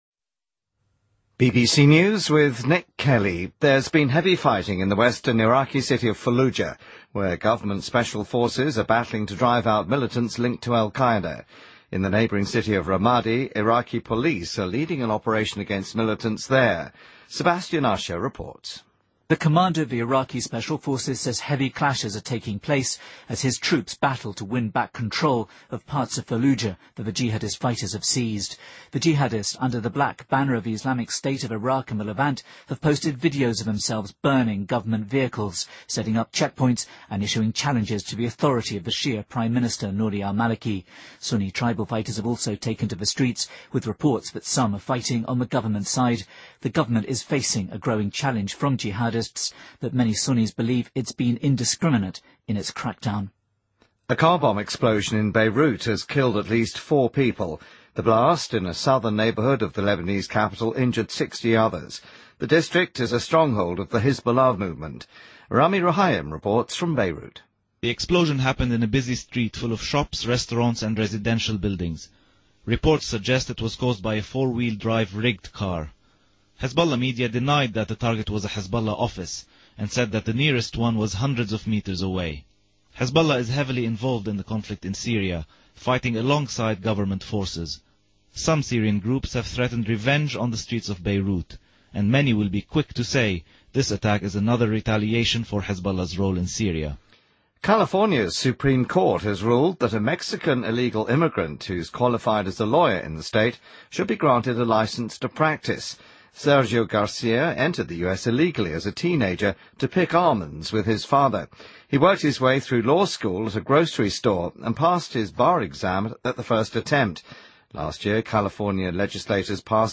BBC news,2014-01-03